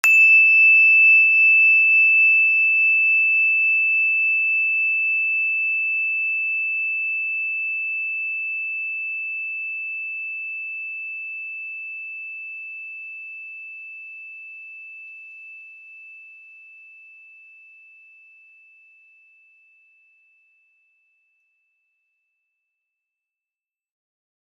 energychime_plastic-E6-ff.wav